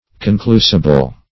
Conclusible \Con*clu"si*ble\, a. Demonstrable; determinable.